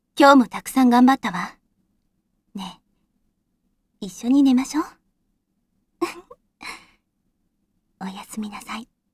システムボイス　菊花